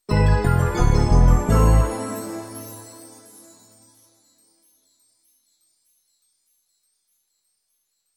Mystic Fill 1
bonus-sound fill film-production game-development intro magical mystic mystical sound effect free sound royalty free Movies & TV